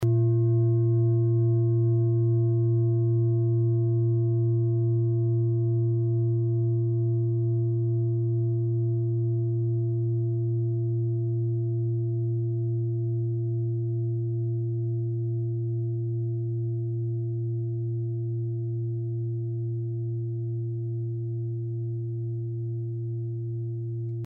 Klangschalen-Typ: Tibet
Klangschale Nr.3
Gewicht = 1960g
Durchmesser = 27,2cm
(Aufgenommen mit dem Filzklöppel/Gummischlegel)
klangschale-set-6-3.mp3